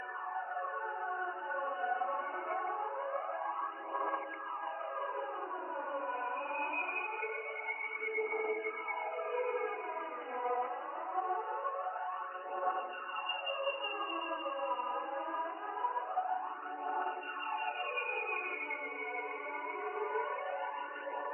描述：调：Fmin TEMPO：90bpm 一个奇怪的Vocoder床的循环。
Tag: 90 bpm Weird Loops Pad Loops 3.59 MB wav Key : F